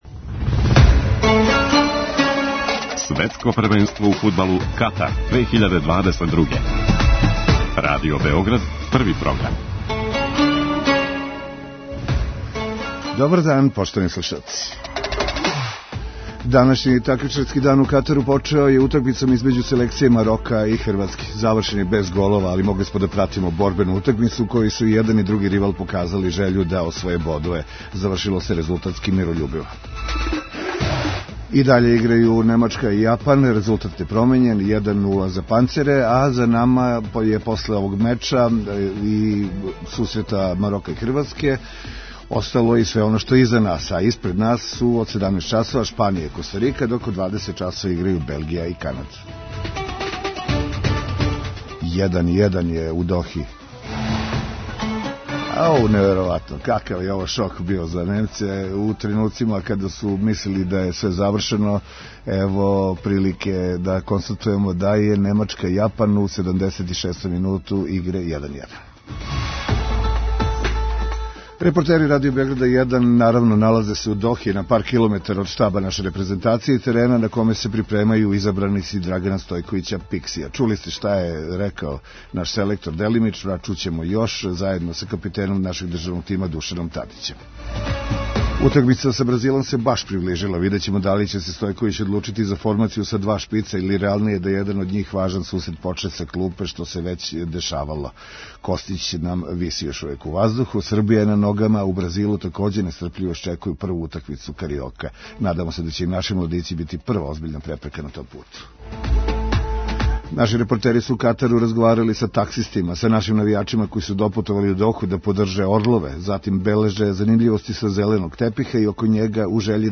Репортери Радио Београда 1 налазе се у Дохи, на пар километара од штаба наше репрезентације и терена на коме се припремају изабраници Драгана Стојковића Пикси.
Наши репортери су у Катару разговарали са таксистима, са нашим навијачима који су допутовали у Доху да подрже `орлове`, бележе занимљивости са зеленог тепиха и око њега, у жељи да нам представе како иде живот током модијалских дана у земљи домаћина шампионата.